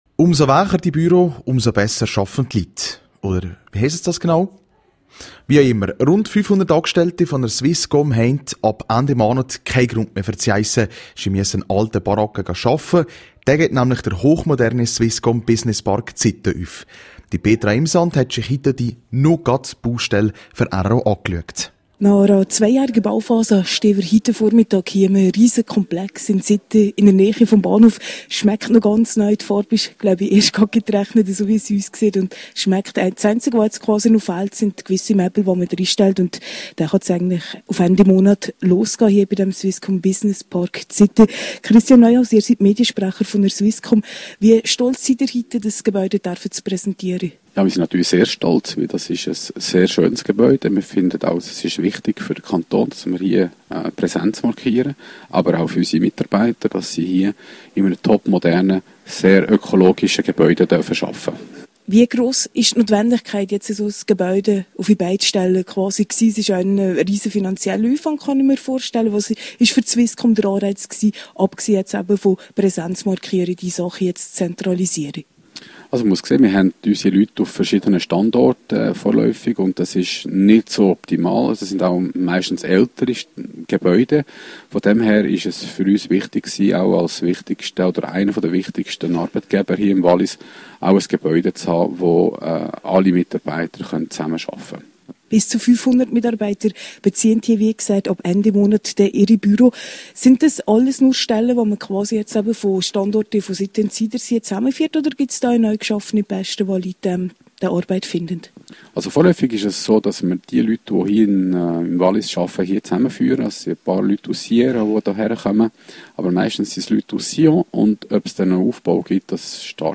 im rro-Interview. So wird das Gebäude mit einer Wärmepumpe auf Grundwasserbasis geheizt und gekühlt.